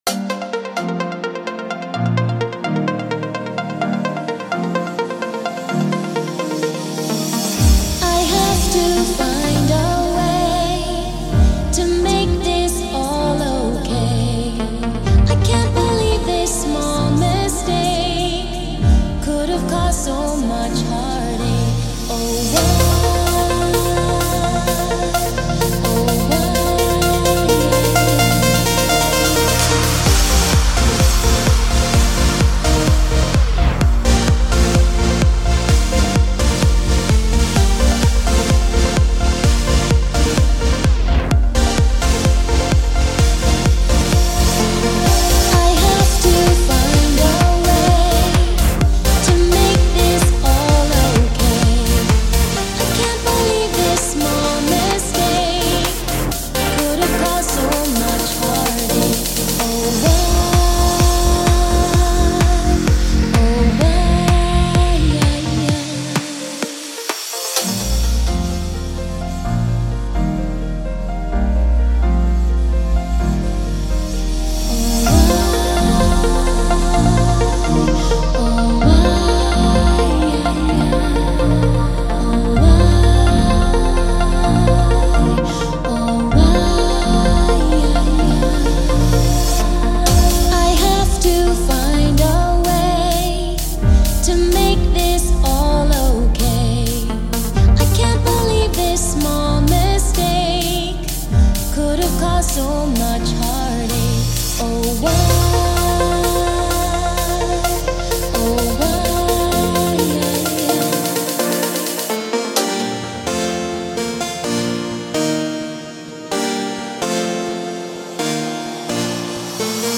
128bpm This Song has only Sylenth1 preset I made myself
genre:remix